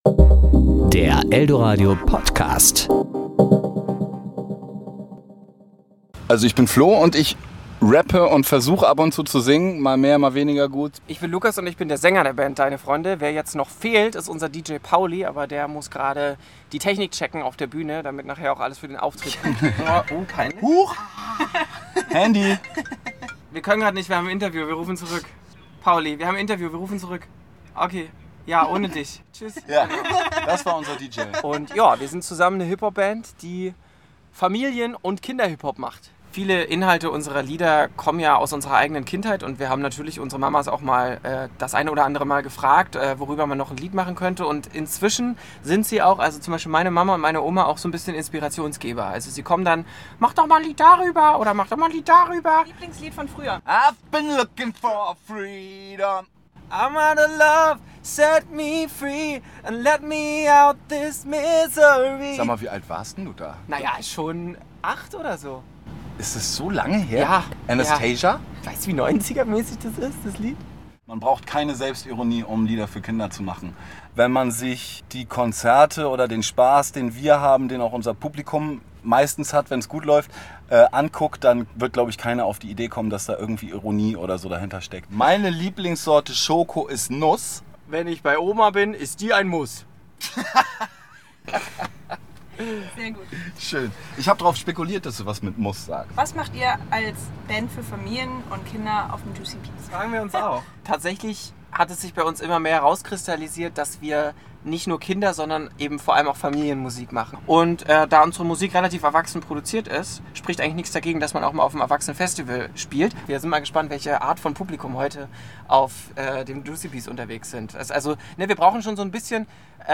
Juicy Beats Kick Off: Interview "Deine Freunde"